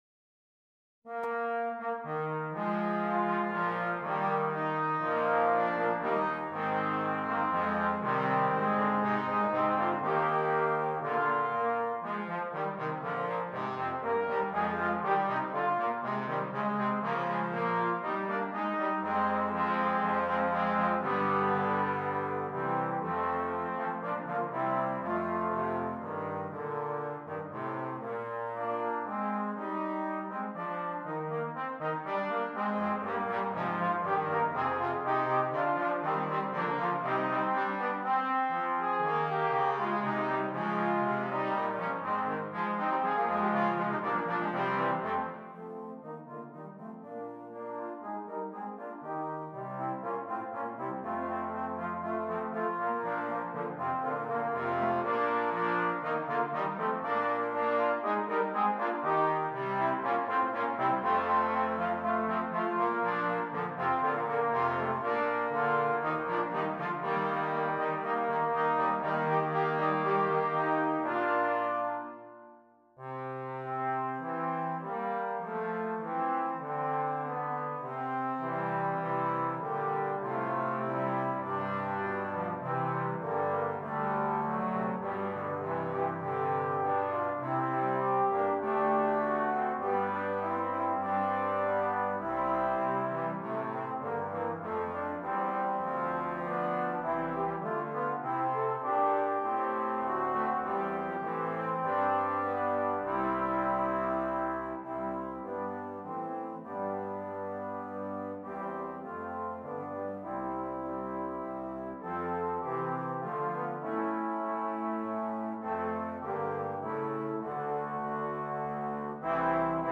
4 Trombones